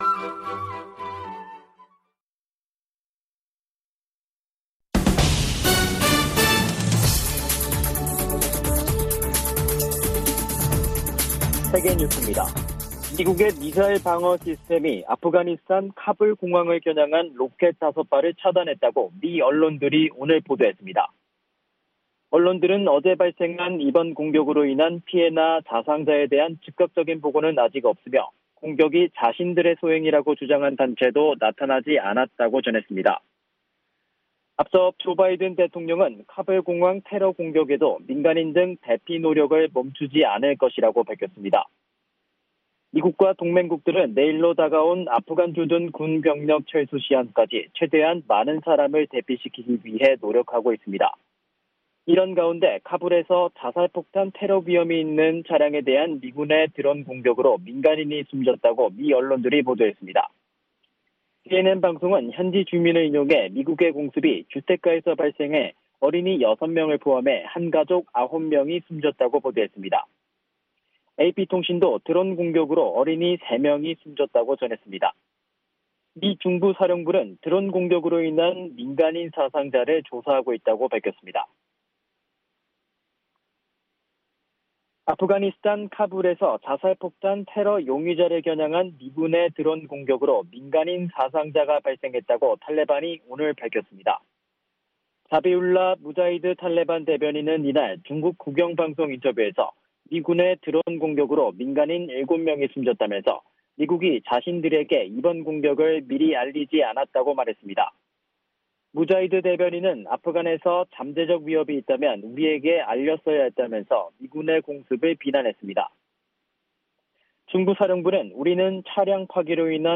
세계 뉴스와 함께 미국의 모든 것을 소개하는 '생방송 여기는 워싱턴입니다', 저녁 방송입니다.